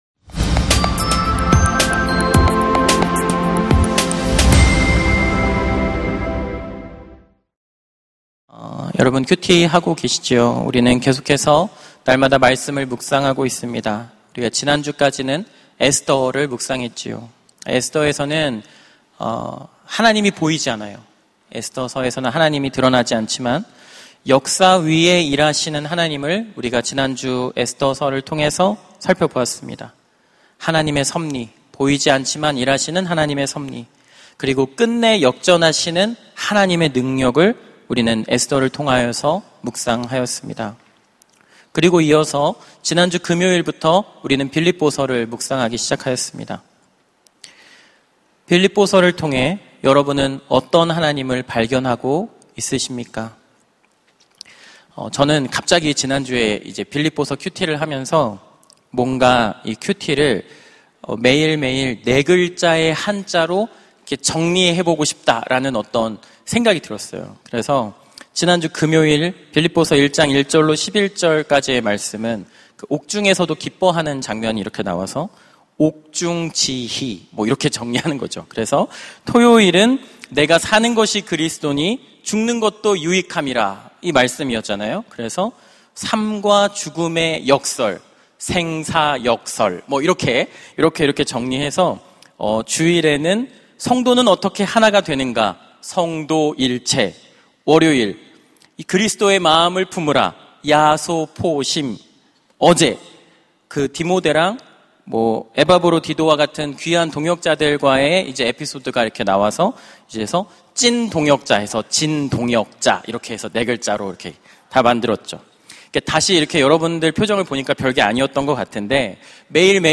설교 : 파워웬즈데이 예수를 얻기 위하여 설교본문 : 빌립보서 3:1-11 설교자